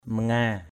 /mə-ŋa:/ manga mz% [Cam M] (d.) biển = mer = sea.